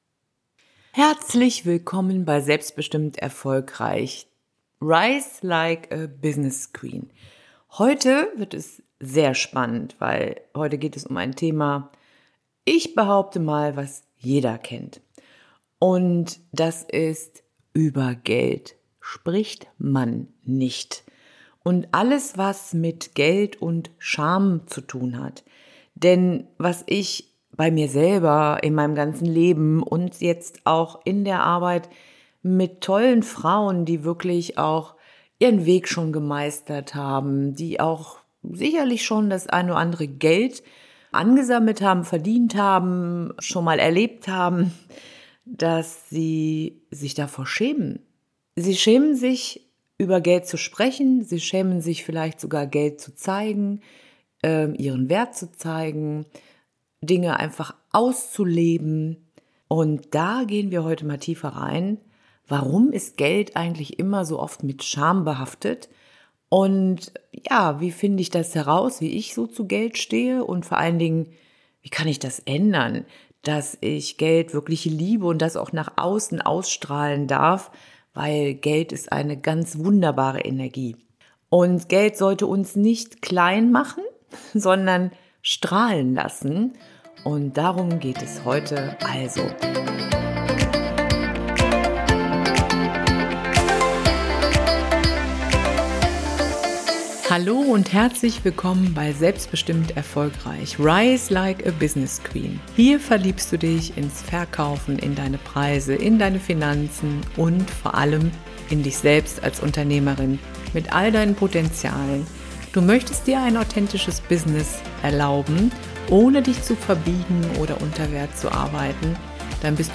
In dieser Solo-Folge von Selbstbestimmt Erfolgreich – Rise like a Business Queen nehme ich dich mit auf einen ehrlichen Deep Dive in ein Thema, das viele Frauen kennen, aber nur selten offen ansprechen: Geld & Scham.